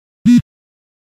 Короткий виброзвук на телефоне при смс